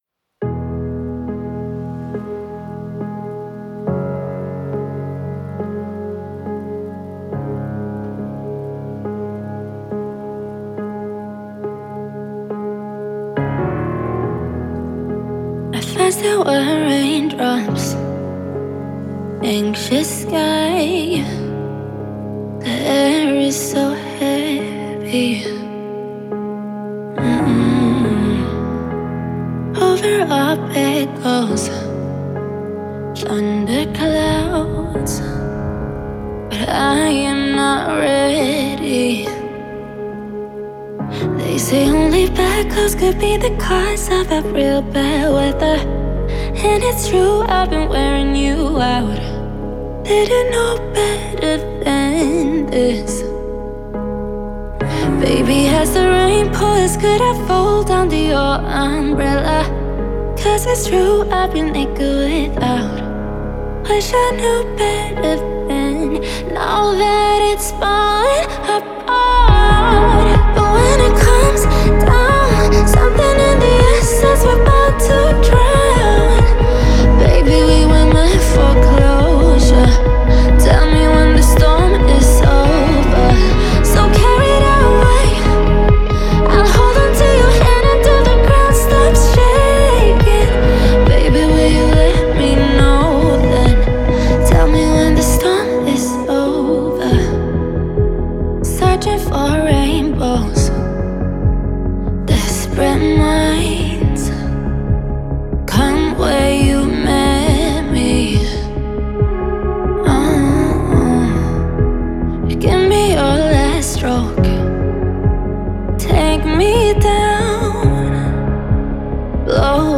мощным и эмоциональным вокалом